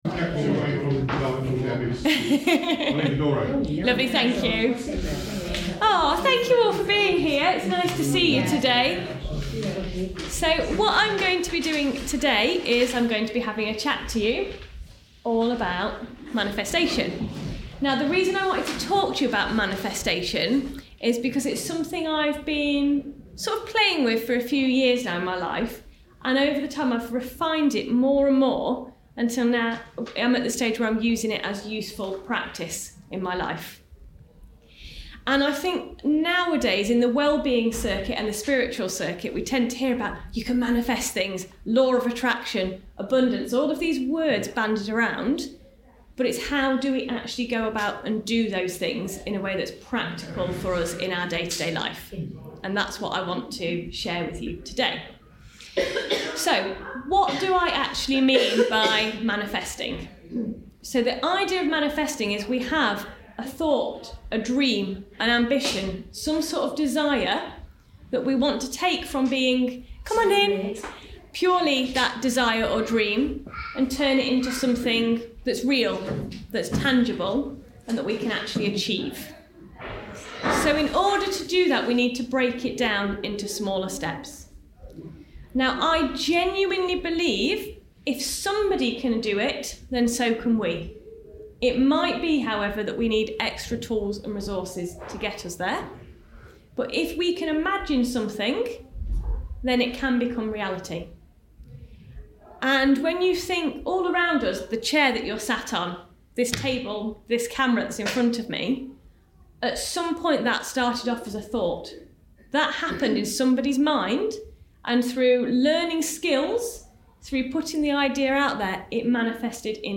During the Lincolnshire Well-Being Show, we recorded some talk presentations. Today’s recording is about Prosperity Through Manifestation.
We have chosen not to edit the recording as listeners will attain greater insight into the message within the presentation.